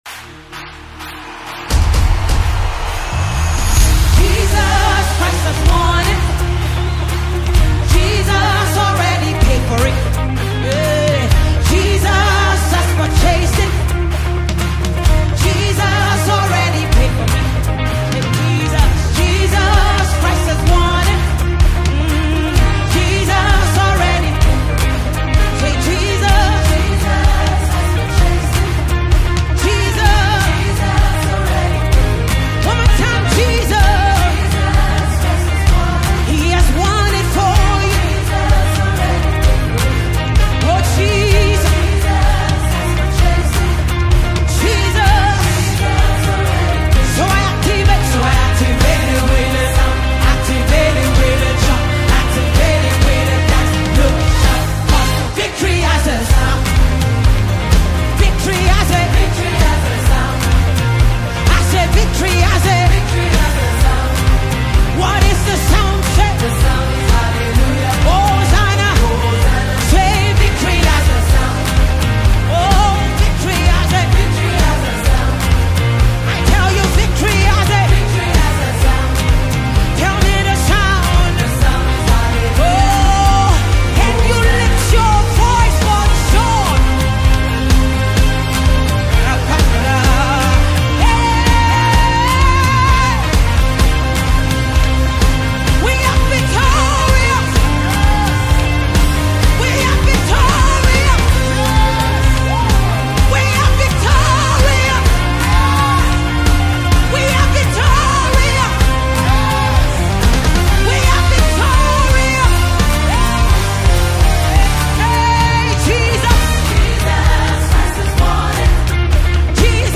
Nigeria talented gospel music singer and songwriter